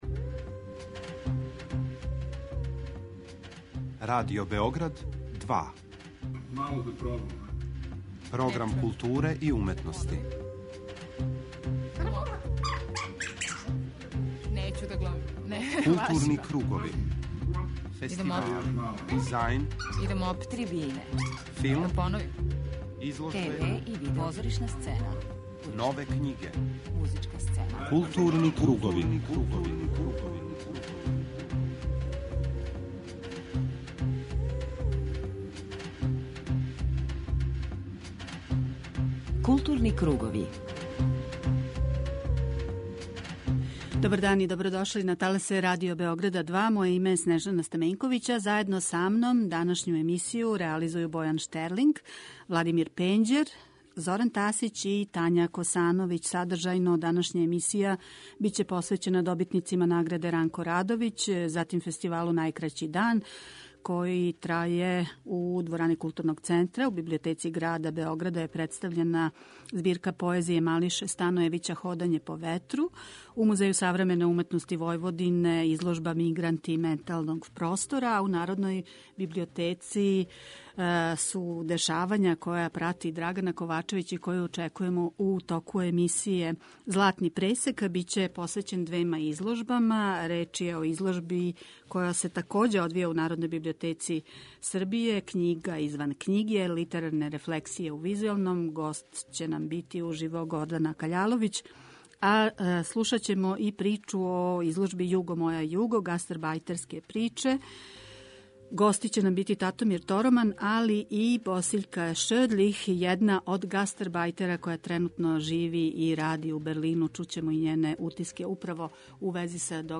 преузми : 41.16 MB Културни кругови Autor: Група аутора Централна културно-уметничка емисија Радио Београда 2.